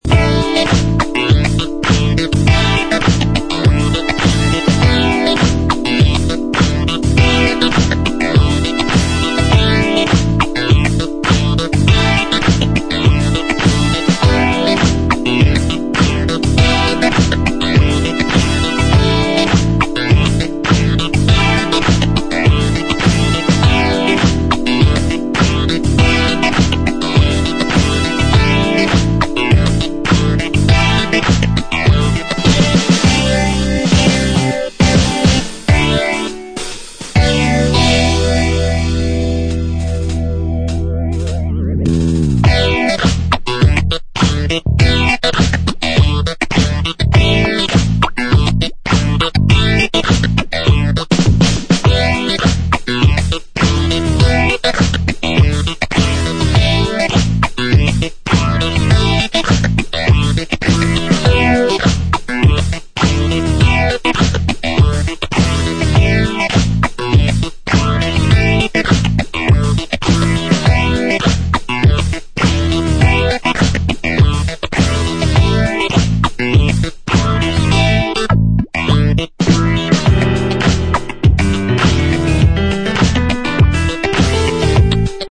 Retro early Italo Balearic Disco tracks